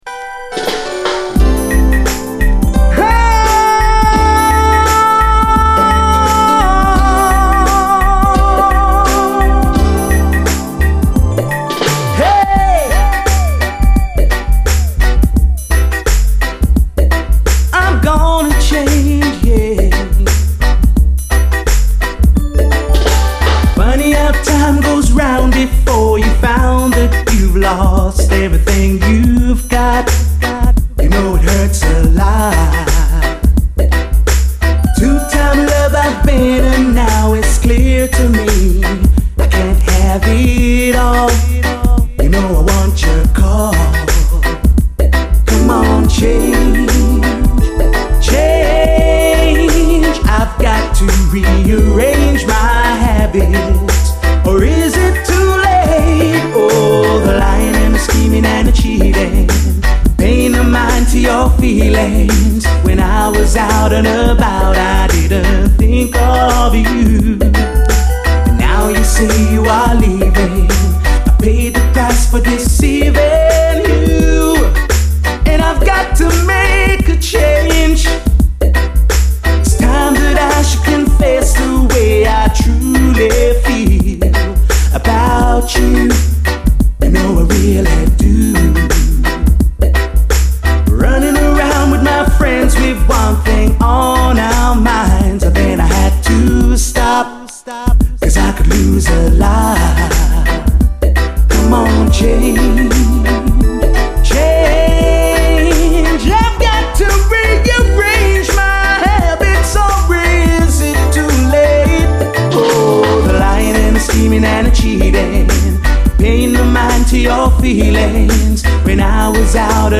REGGAE
美メロ美ハーモニーのビューティフル90’S UKラヴァーズ！